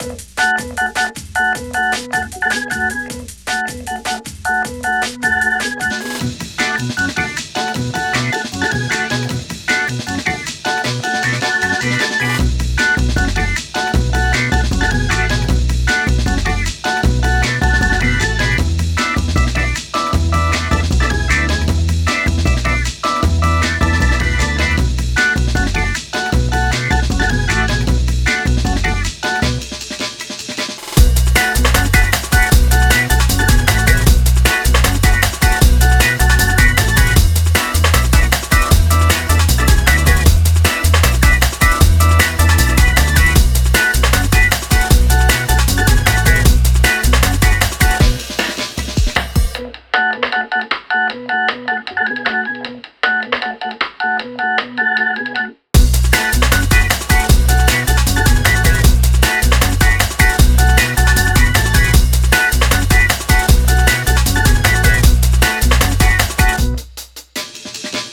happy-jungle.wav